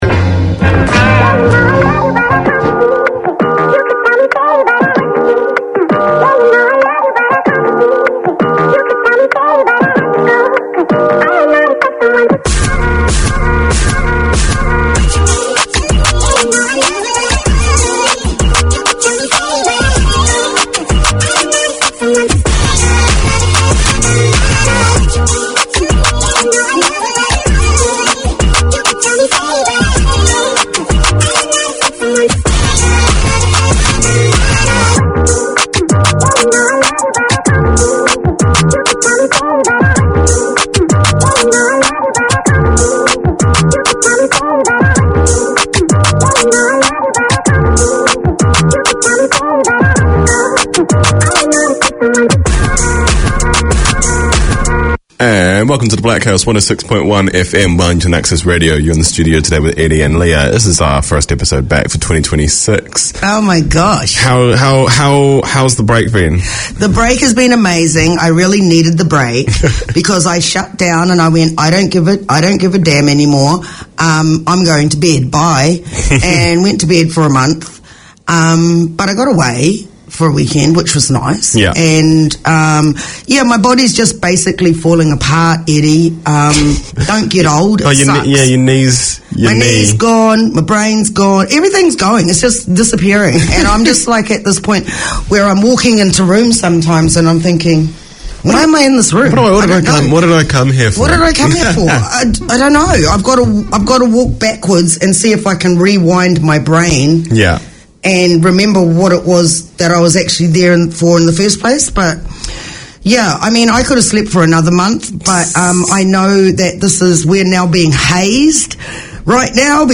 In this one-off special, Planet FM presents a snapshot of Te Matatini 2023, with vox-pops and interviews undertaken in the marketplace at Ana Wai / Eden Park where the festival took place. A celebration of the best of Kapa Haka across Aotearoa New Zealand, this year's Te Matatini festival was hosted by Ngāti Whātua Ōrākei.